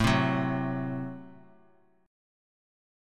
Listen to Am6 strummed